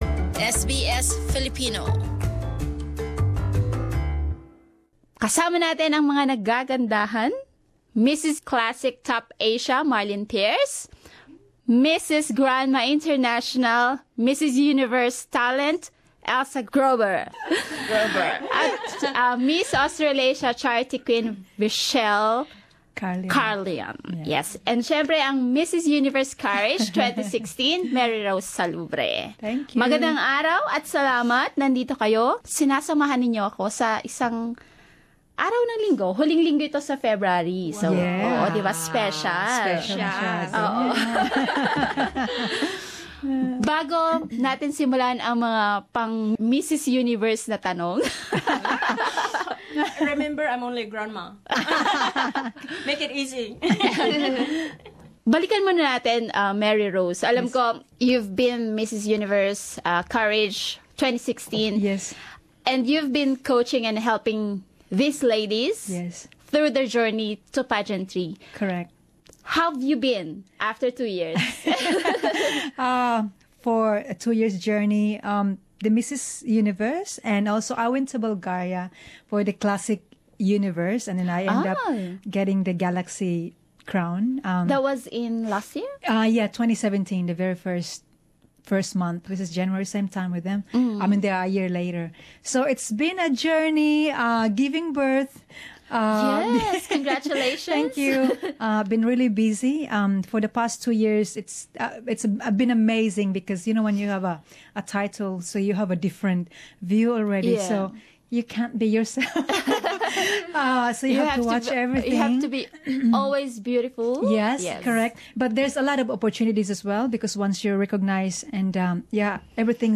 Watch the video below of the live studio interview: Share